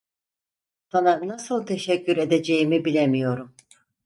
/teʃecˈcyɾ/